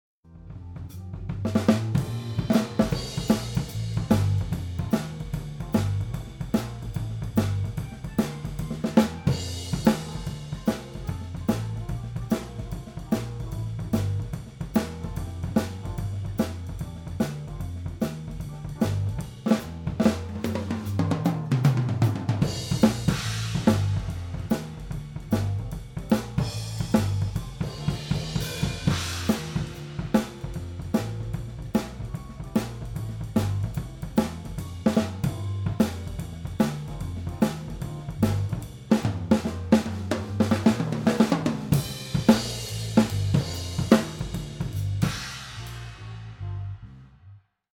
Live-Mitschnitt bei Kneipengig - Bericht mit Audiobeispielen
Die AKGs waren in AB (ca. 90cm auseinander) mittig über der Snare postiert - ca. 1,5 m über der Snaredrum.
Hier die Soundbeispiele - die Einzelsignale sind roh und unbearbeitet - die Mischung natürlich schon...
Overheads
Overheads_C414B-ULS_AB.MP3